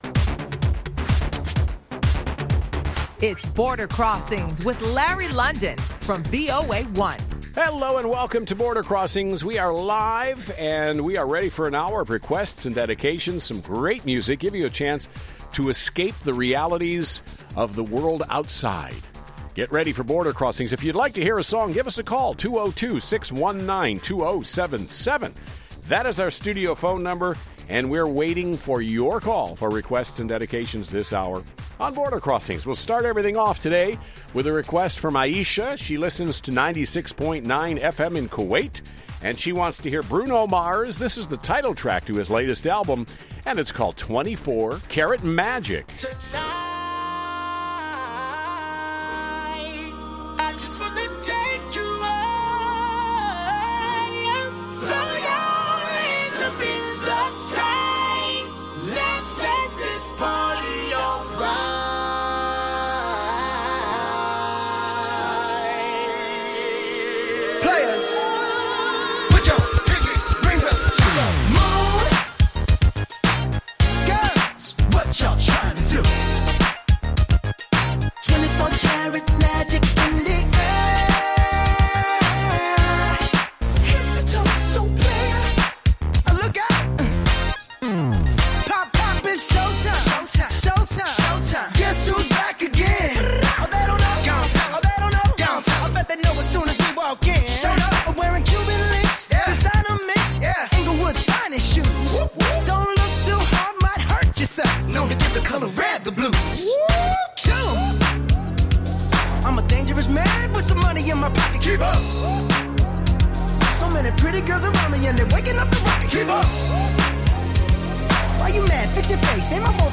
live worldwide international music request show